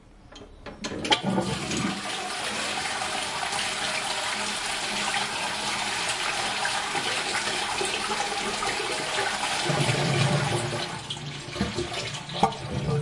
Flushing a Toilet
描述：Flushing a Toilet
标签： Flush Flushing bathroom Toilet
声道立体声